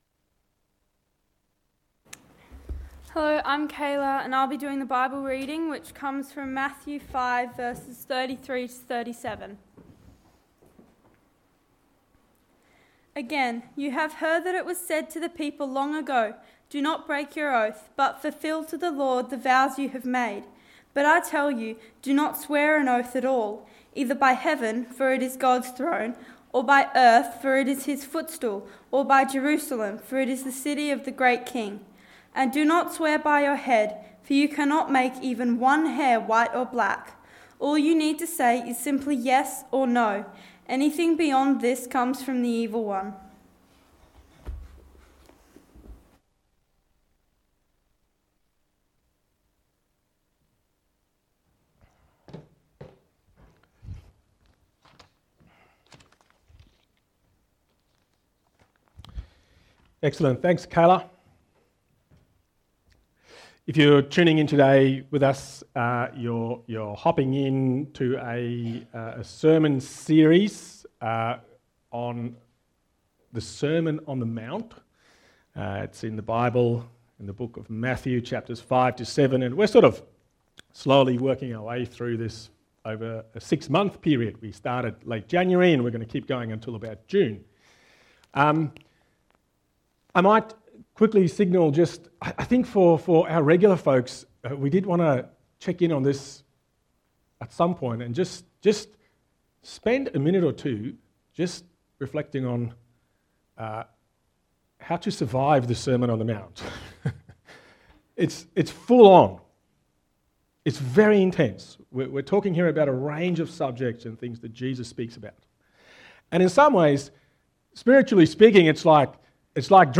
Text: Matthew 5: 33-37 Sermon